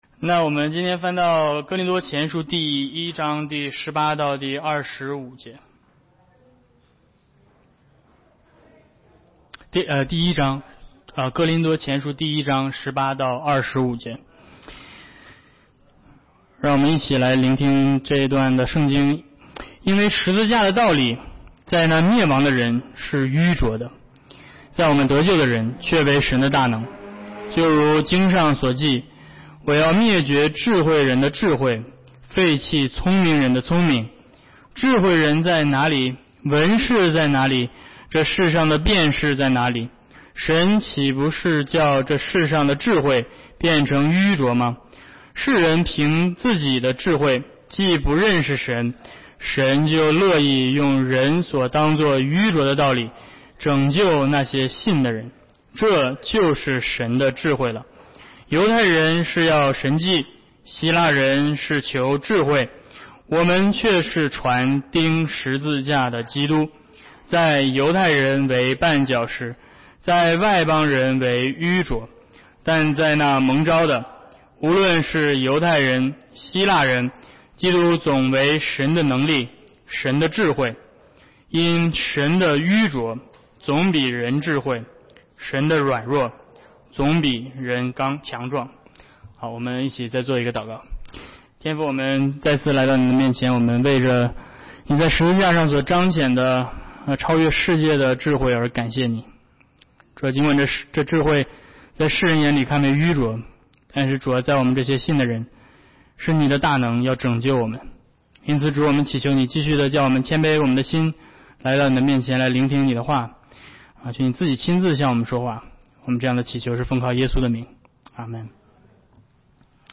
恩约讲座